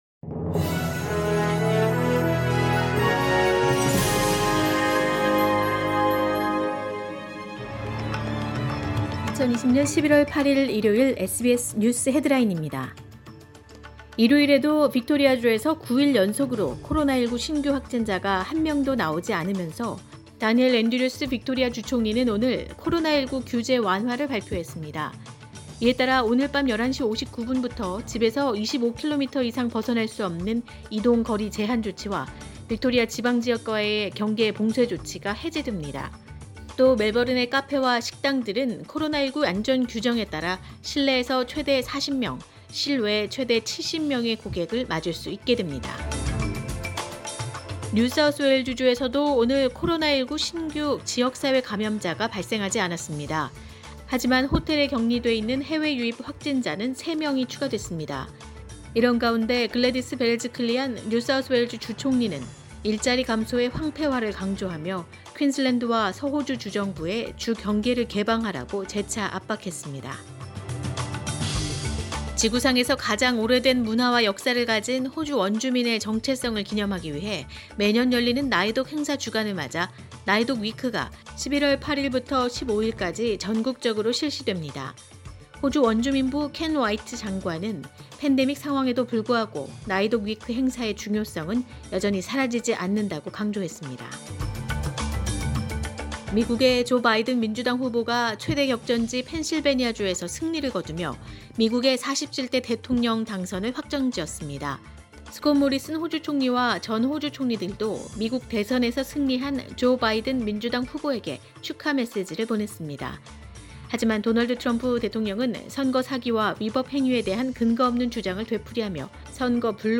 2020년 11월 8일 일요일 오전의 SBS 뉴스 헤드라인입니다.